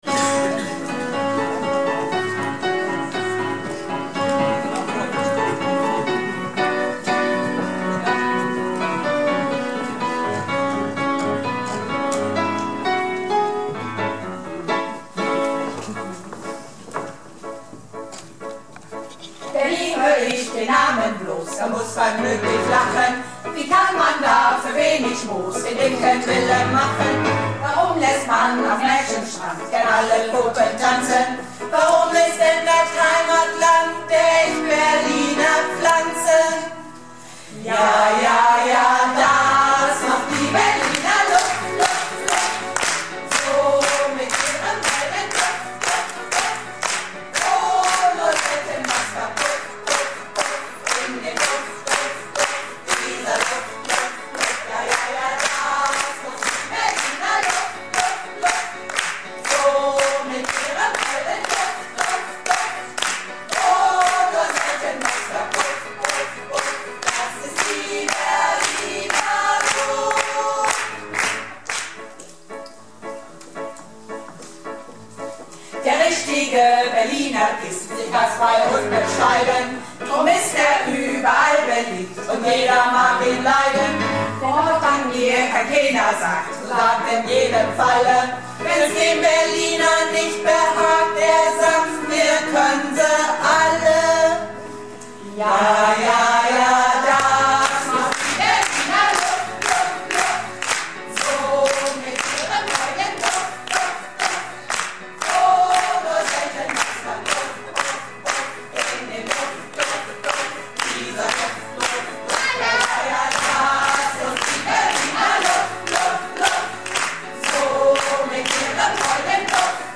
Theater - "Du musst es wagen - Sünner Tegenstöten word nümms deftig" am 18.03.2012 in Emden